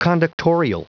Prononciation du mot conductorial en anglais (fichier audio)
Prononciation du mot : conductorial